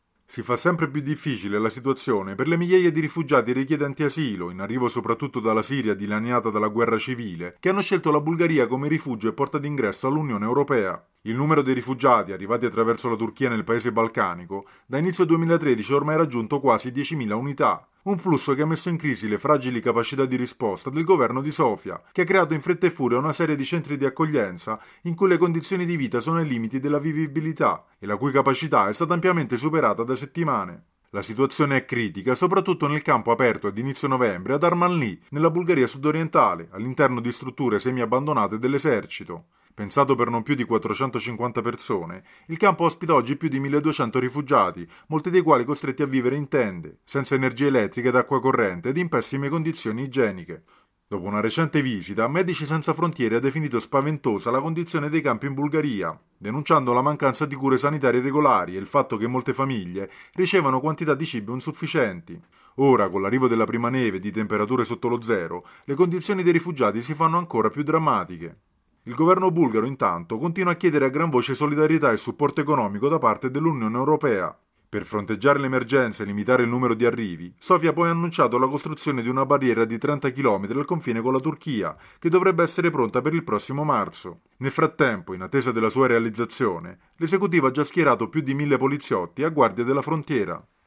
per il GR di Radio Capodistria